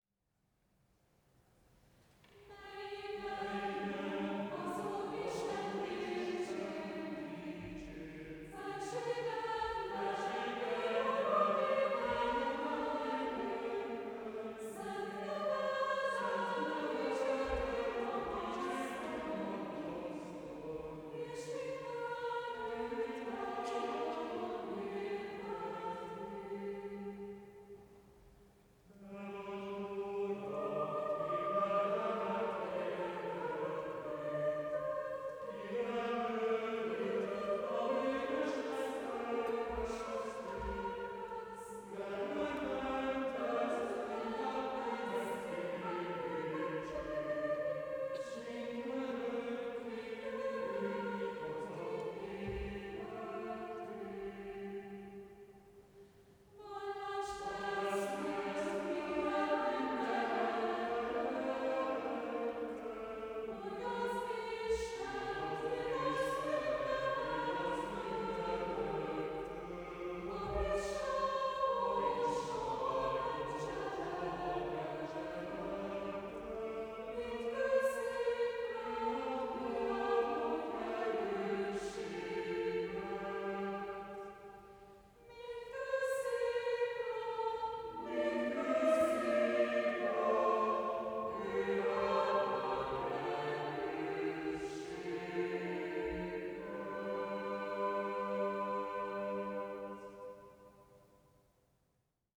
Root > trekarchiv > 2019 > Kántus > Évzáró koncert 2019 > Hang